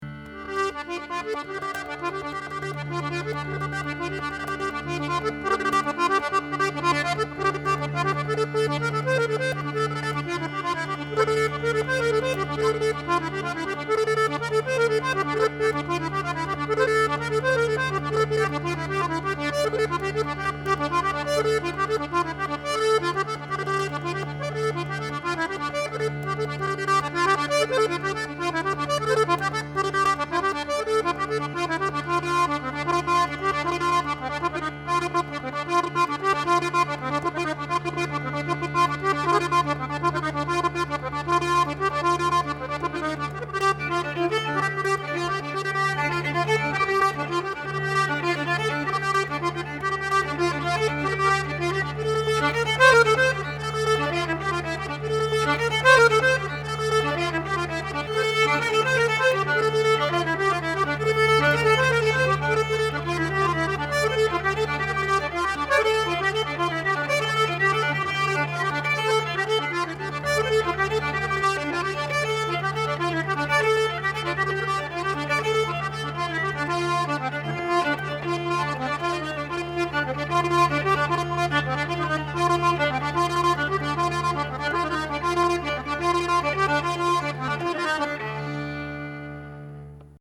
Violin
Button Accordion, Piano
Bass Cello, Tilinca
Genre: Klezmer.